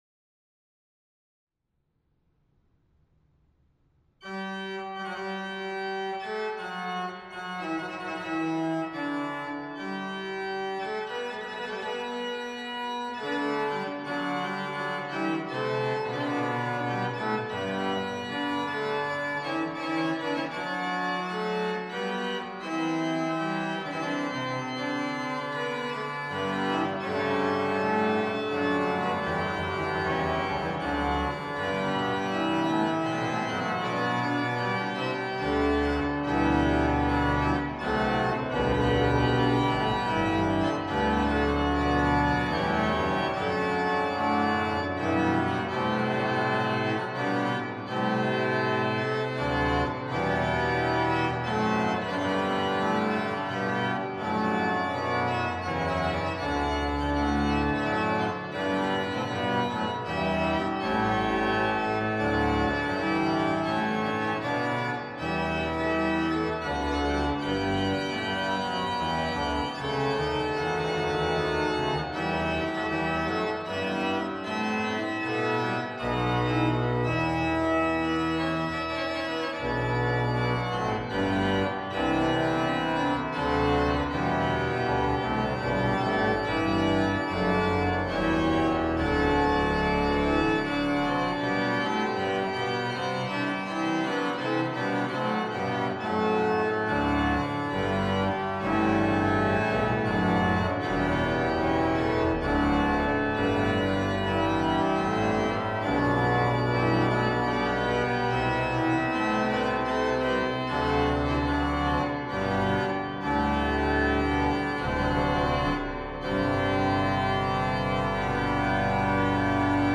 The recording was done on the sample set of the Müller-organ in the Sint Bavokerk in Haarlem by Voxus Organs